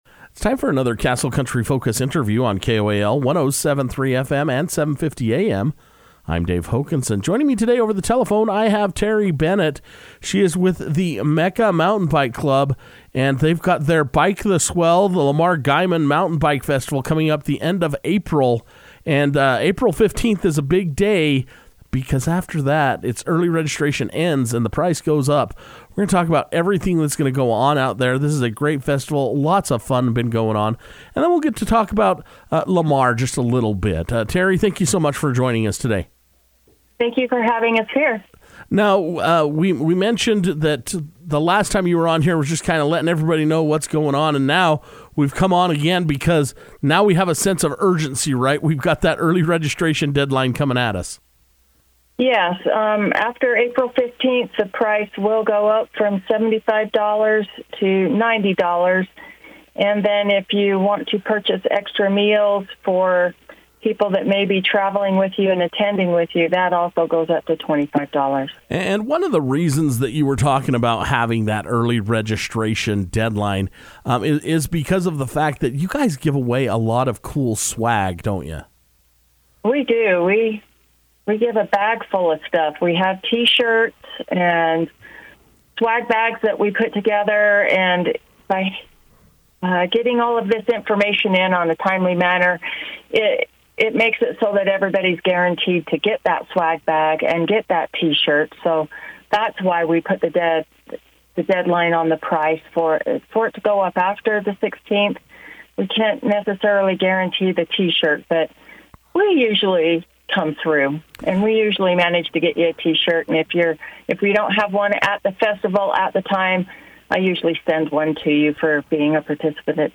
took time to come into the Castle Country Radio to share all the details with listeners.